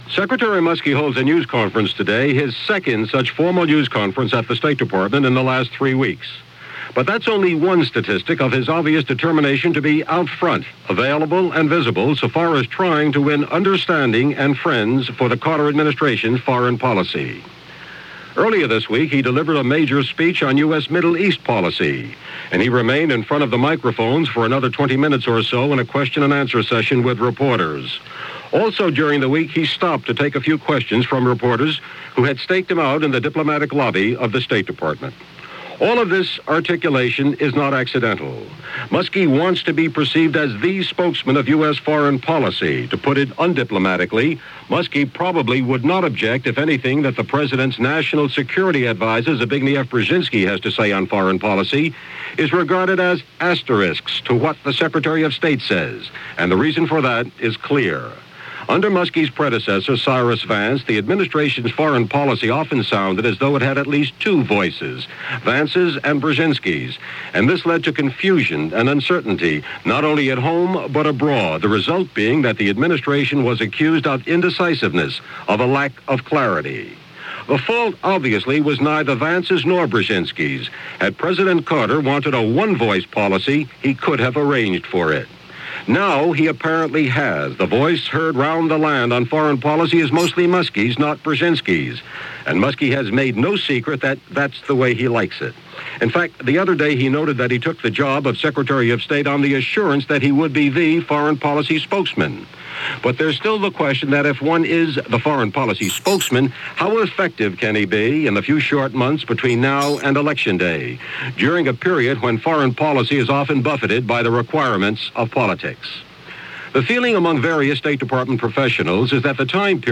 June 13, 1980 – CBS World News Roundup + First Line Report – Hourly News – Gordon Skene Sound Collection –